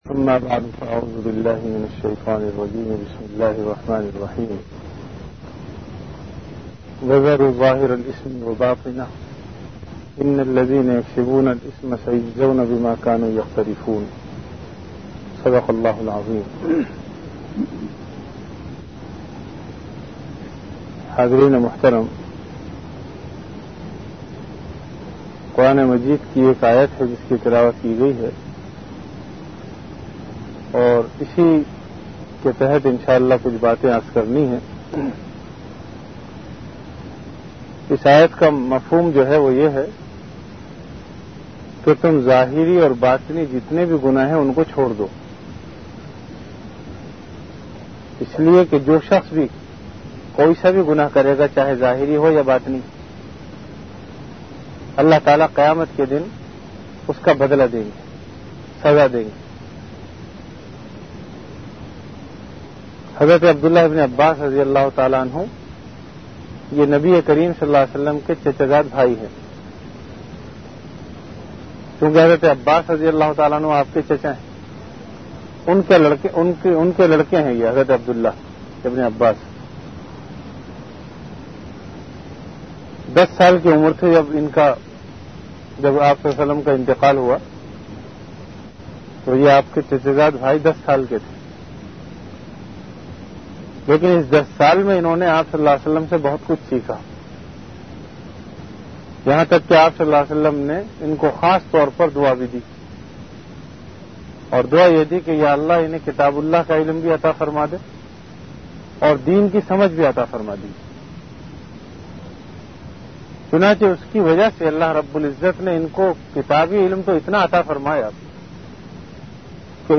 An Islamic audio bayan
Delivered at Jamia Masjid Bait-ul-Mukkaram, Karachi.
Bayanat · Jamia Masjid Bait-ul-Mukkaram, Karachi